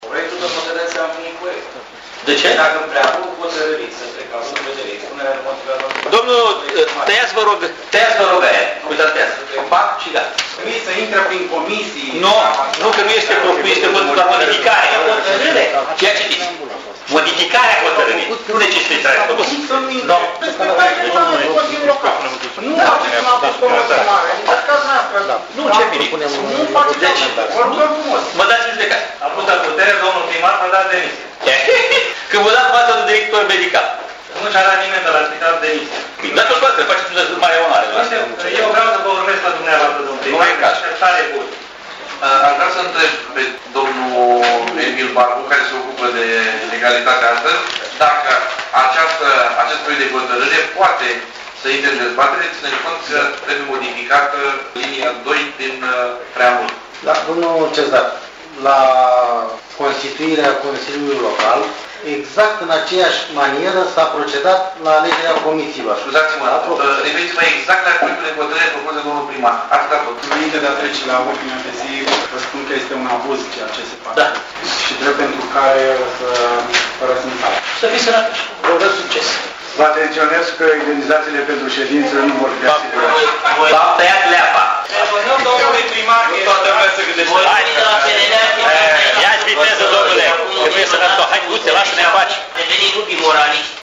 Reprezentanţii PNL, PDL şi PER au părăsit sala de şedinţă în semn de protest la propunerea executivului de a schimba componenţa comisiilor de lucru ale Consiliului Local. Iniţiativa a fost enunţată de primarul Alexandru Stoica în deschiderea şedinţei, cu propunerea de suplimentare a ordinii de zi, iar consilierii locali din opoziţiei au ripostat şi au reproşat executivului că acesta este un abuz: